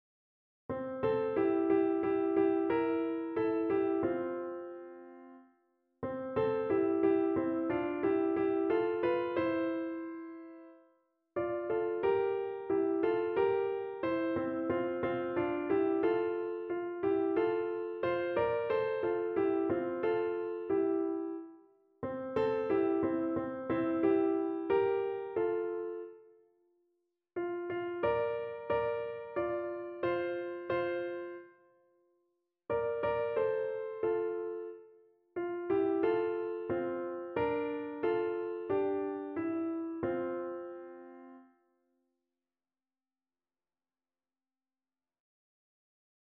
Evangeliumslieder
Notensatz (4 Stimmen gemischt)